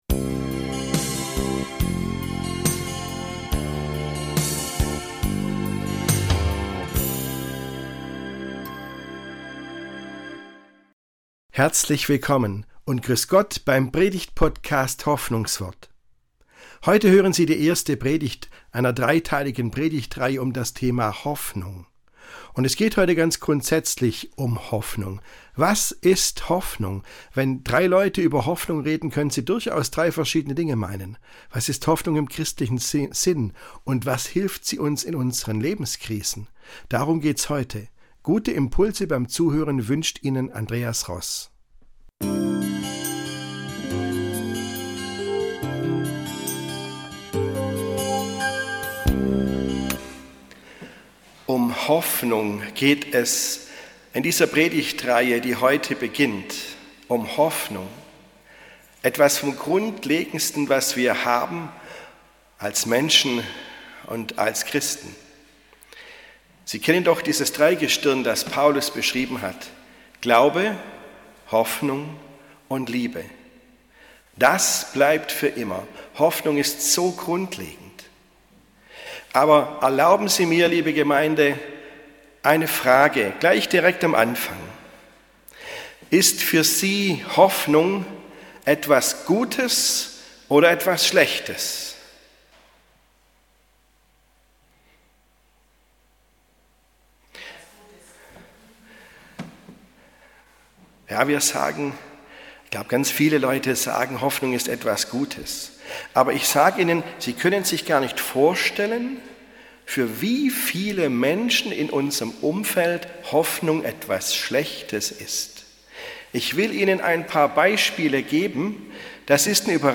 Was ist Hoffnung im christlichen Sinn? (13.10.2024, Predigtreihe „Hoffnung“, Teil 1)